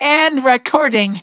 synthetic-wakewords
ovos-tts-plugin-deepponies_Teddie_en.wav